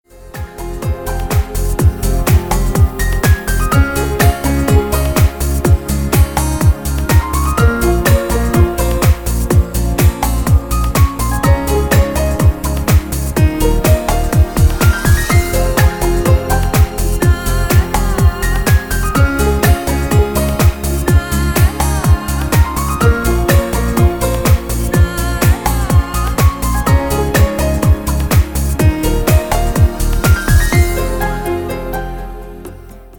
Мелодичная нарезка на рингтон